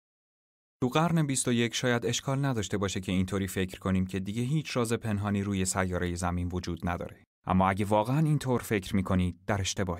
• 5Persian Male No.3
Commercial